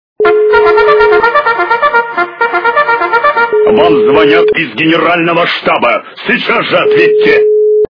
» Звуки » Смешные » Звонок из штаба - Вам звонят из генерального штаба. Немедленно ответьте!